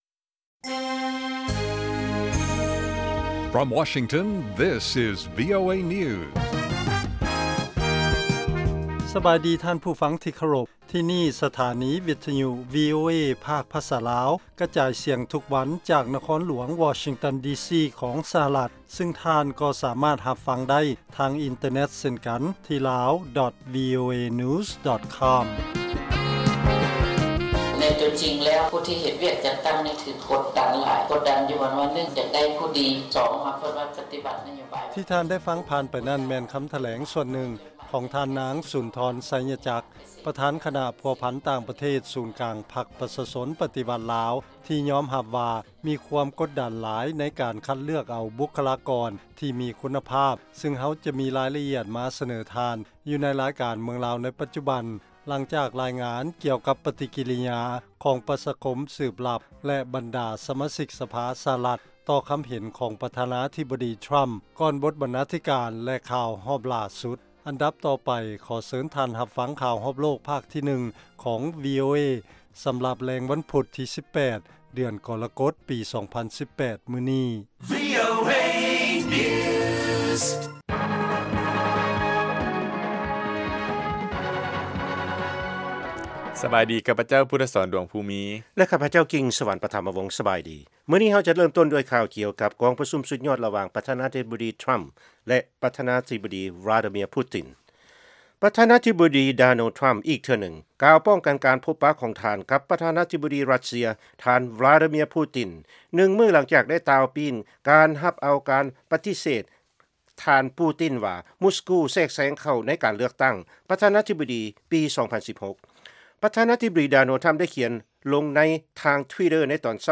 ວີໂອເອພາກພາສາລາວ ກະຈາຍສຽງທຸກໆວັນ ເປັນເວລາ 30 ນາທີ.